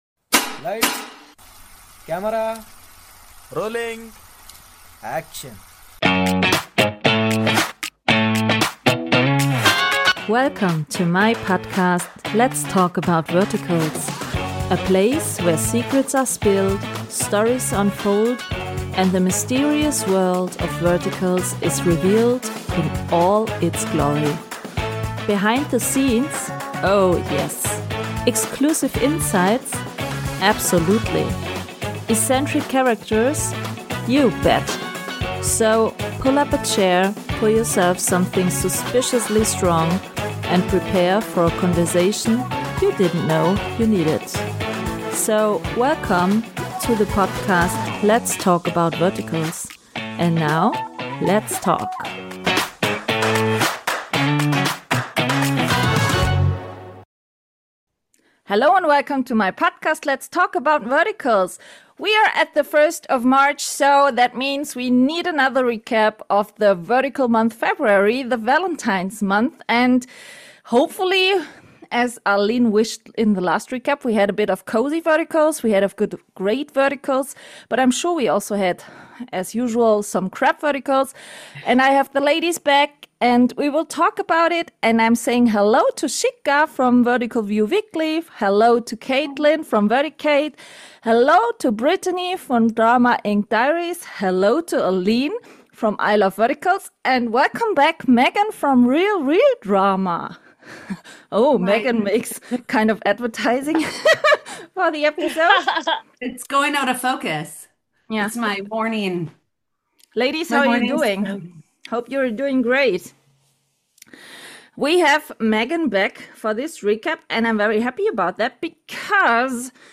A refreshingly entertaining yet critically reflective conversation you don’t want to miss  Enjoy listening!